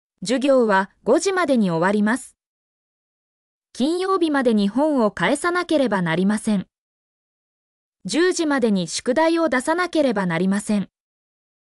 mp3-output-ttsfreedotcom-25_FUpSnlDu.mp3